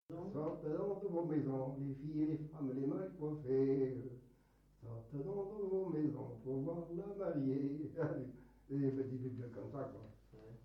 Enumératives - Nombres en décroissant
circonstance : fiançaille, noce
Pièce musicale inédite